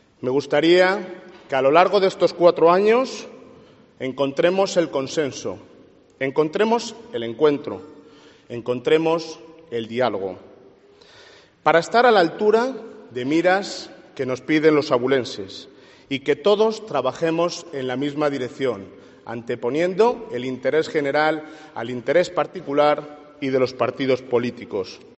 Alcalde de Ávila, fragmento del su discurso de investidura